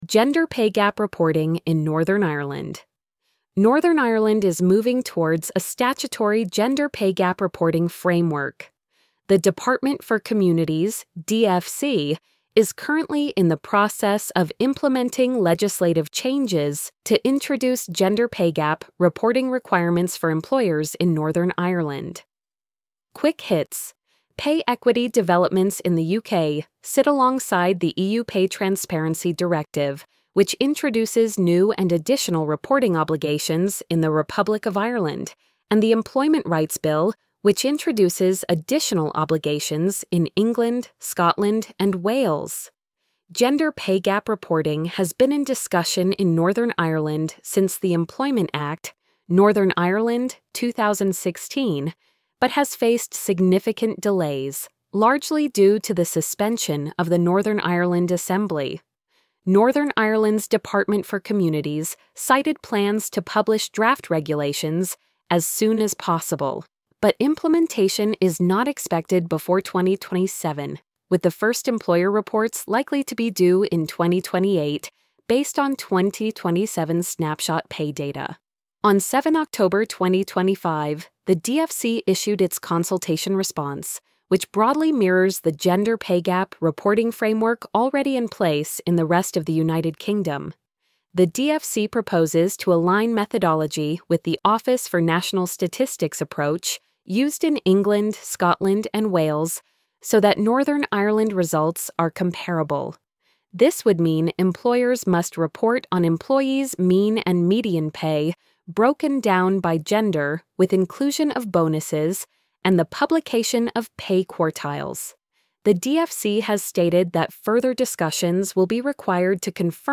gender-pay-gap-reporting-in-northern-ireland-tts.mp3